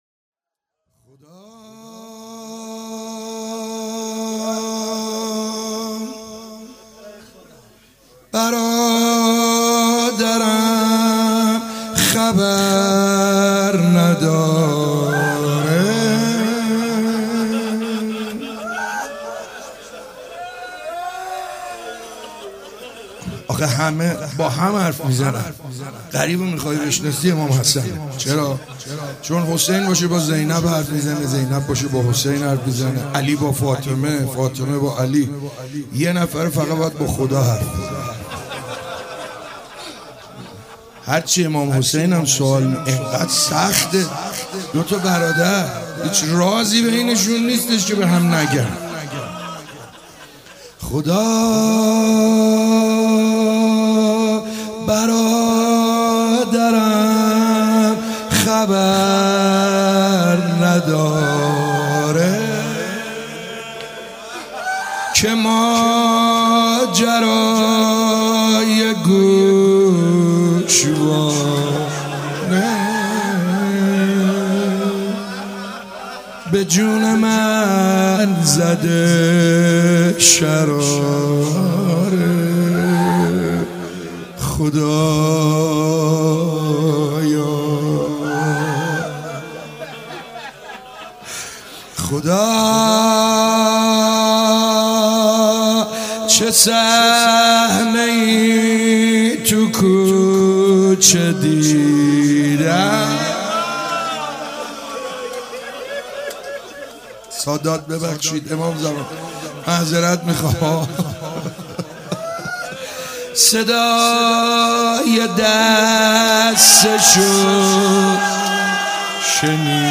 روضه
مداح : سعید حدادیان